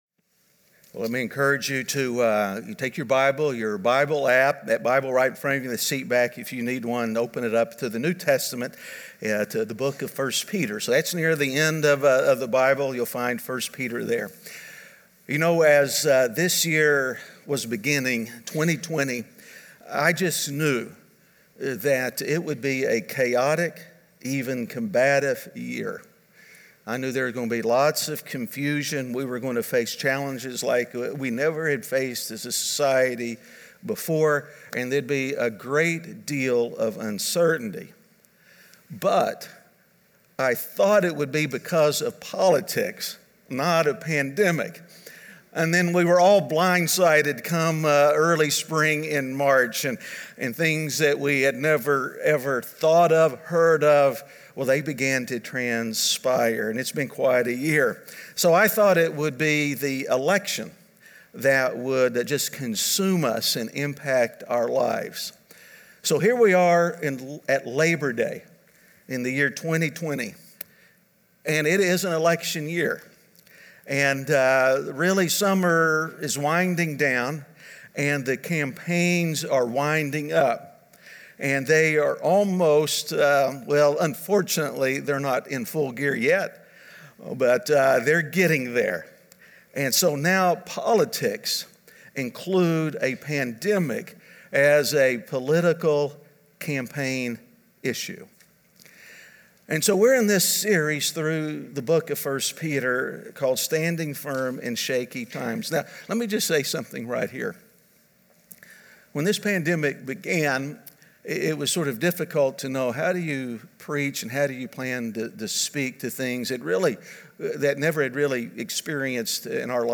Standing Firm In Shaky Times (Week 7) - Sermon.mp3